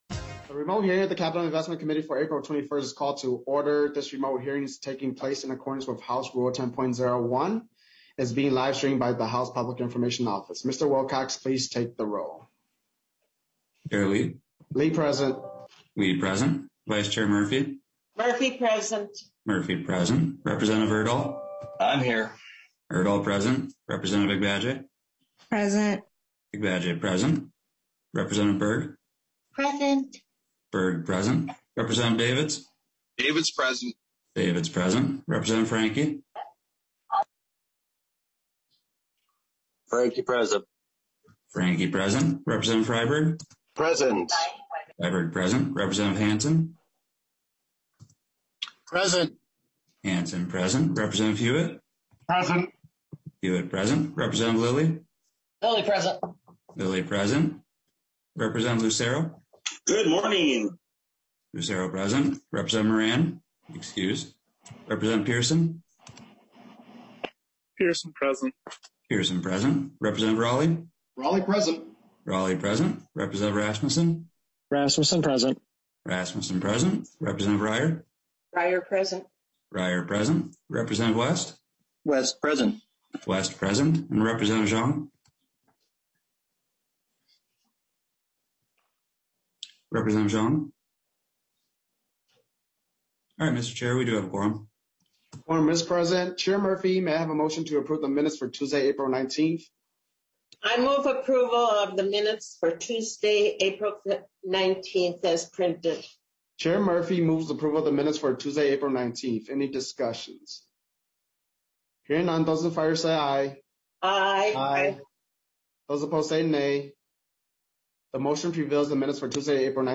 III. HF4670 (Murphy) Claims bill Other bills may be added This hybrid committee hearing will be held in-person in State Capitol Room 120 with remote participation from members and testifiers available.